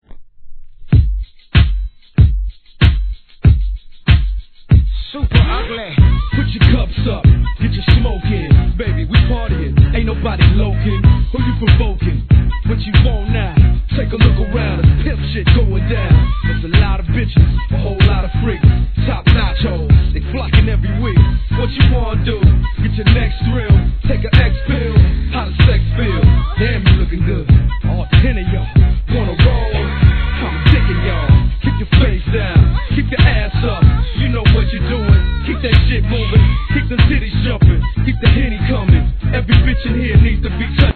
G-RAP/WEST COAST/SOUTH